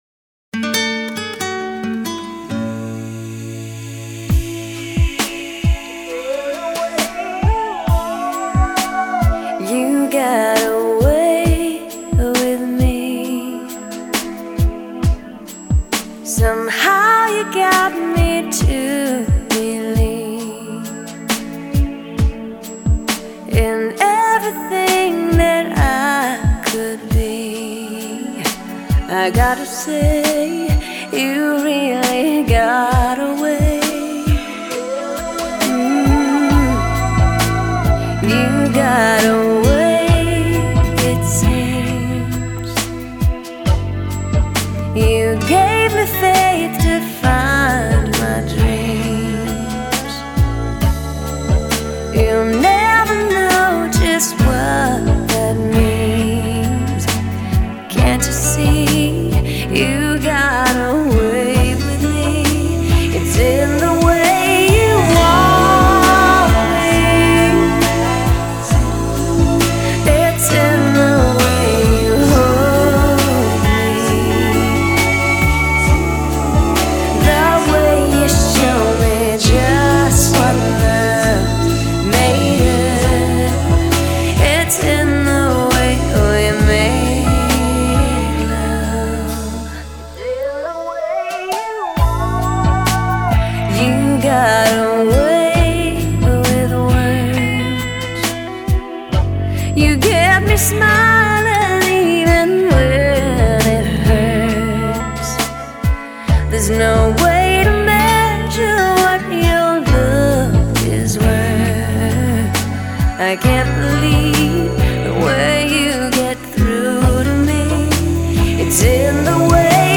所属类别： 原版CD　　原声碟